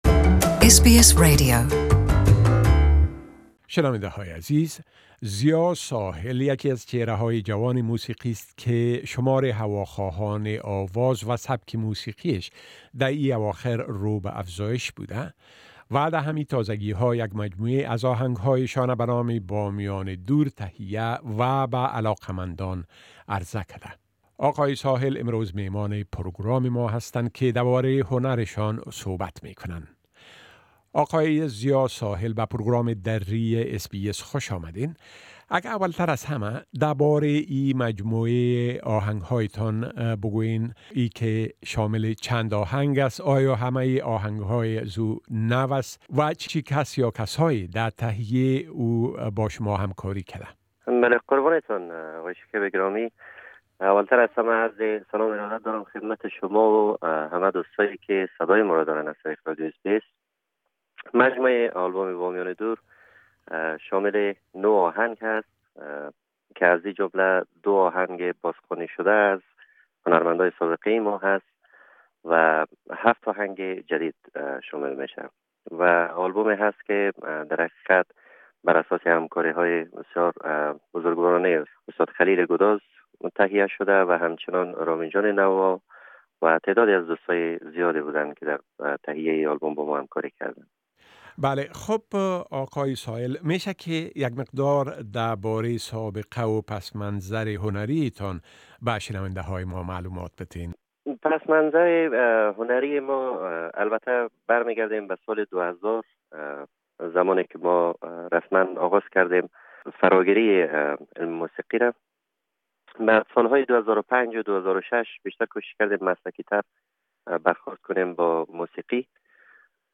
Interview
plus one of his songs